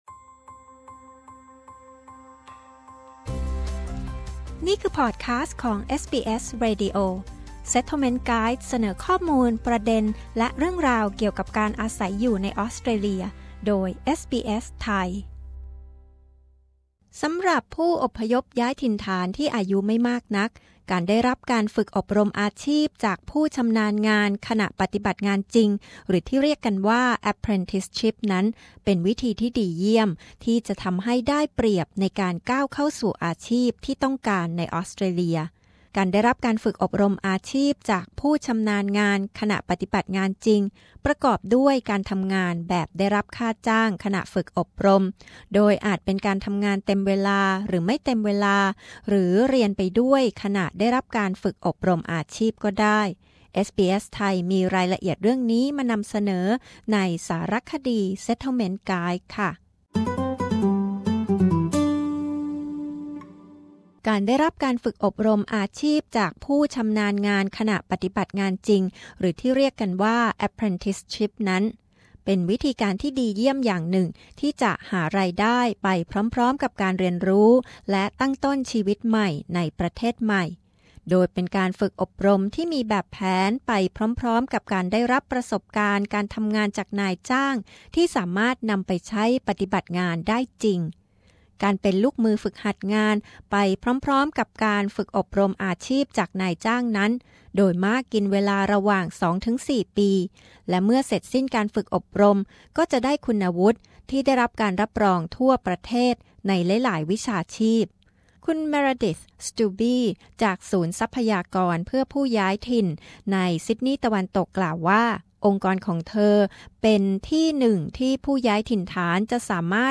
การได้รับการฝึกอบรมอาชีพจากผู้ชำนาญงานขณะปฏิบัติงานจริง หรือ Apprenticeship เป็นวิธีดีเยี่ยม ที่จะก้าวสู่อาชีพที่ต้องการในออสเตรเลีย เอสบีเอส มีรายงานพิเศษแนะแนวทางสำหรับผู้สนใจ